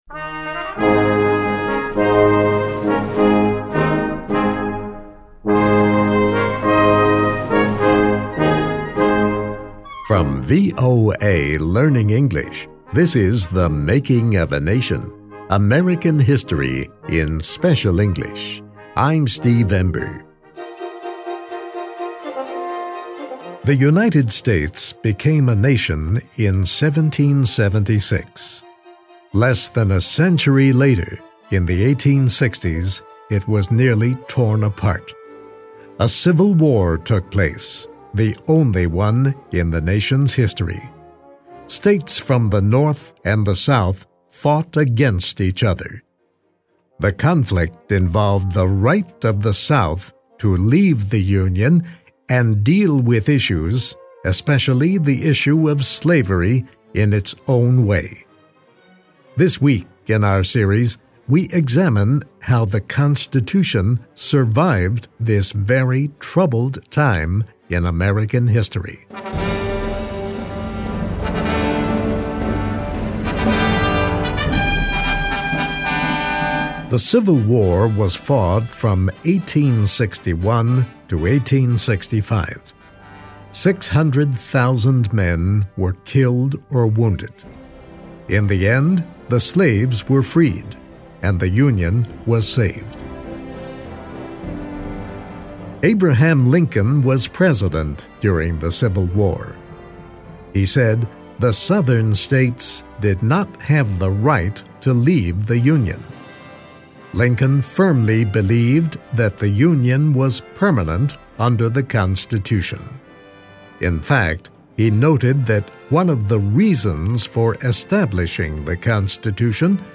► Listen to this story in high-quality 192 kbps audio (or right-click/option-click to save) From VOA Learning English, this is THE MAKING OF A NATION – American history in Special English.